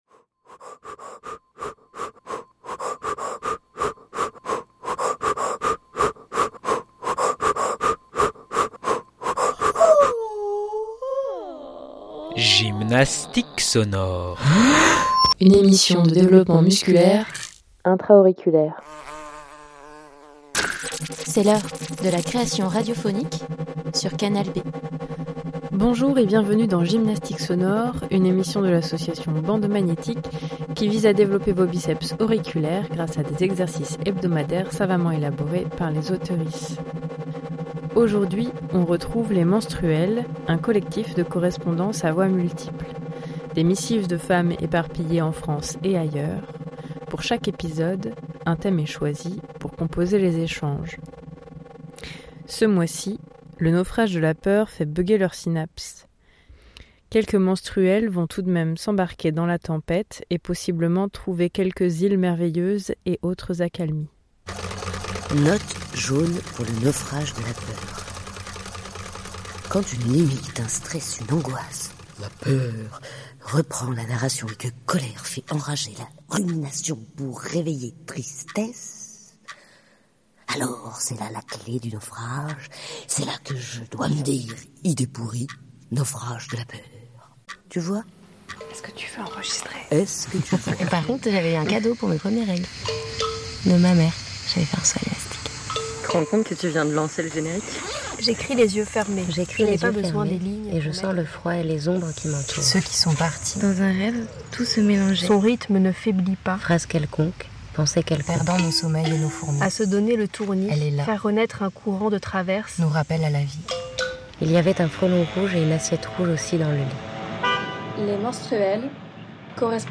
Les Menstruelles #77 - Naufrage de la peur 13/12/2025 60 mn Les Menstruelles , c'est un collectif de correspondances à voix multiples. Des missives de femmes éparpillées en France et ailleurs.